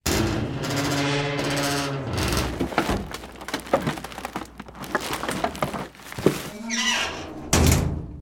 dumpster_0.ogg